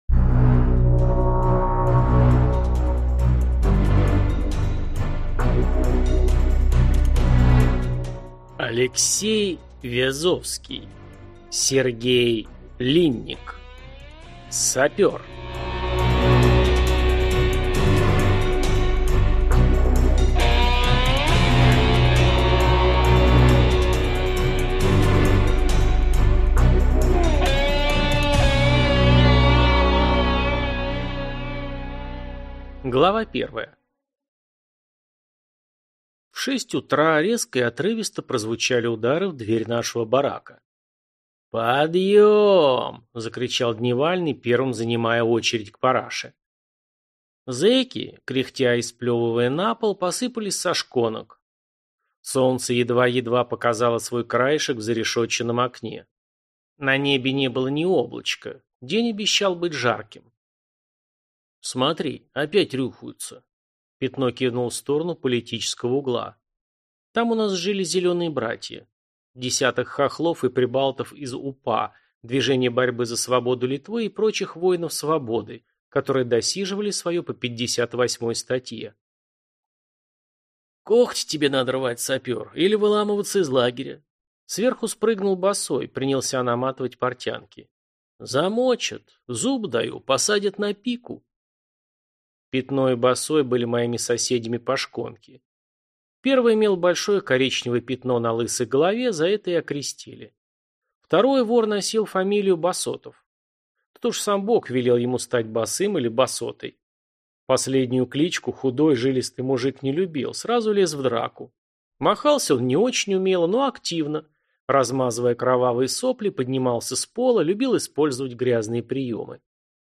Аудиокнига Сапер | Библиотека аудиокниг